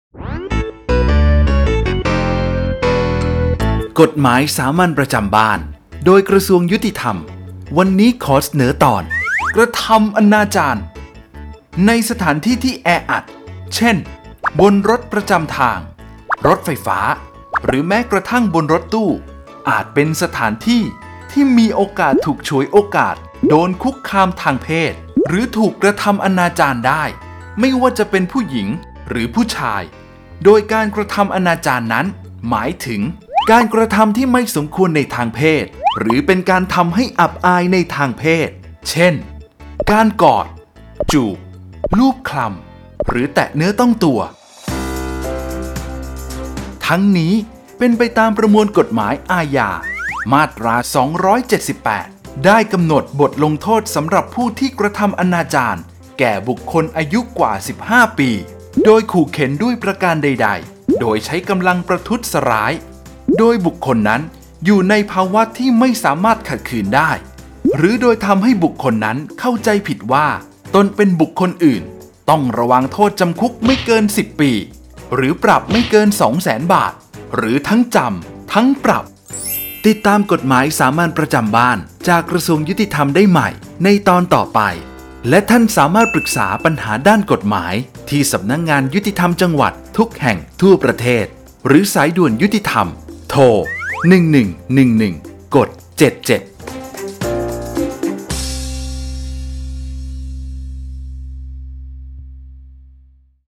กฎหมายสามัญประจำบ้าน ฉบับภาษาท้องถิ่น ภาคกลาง ตอนกระทำอนาจาร
ลักษณะของสื่อ :   บรรยาย, คลิปเสียง